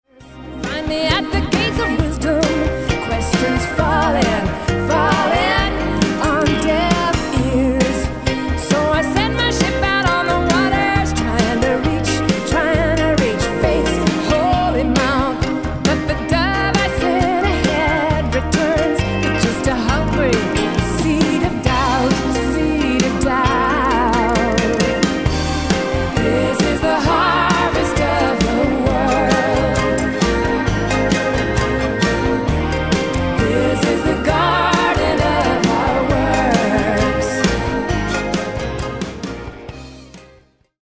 Recorded & mixed at Powerplay Studios, Maur – Switzerland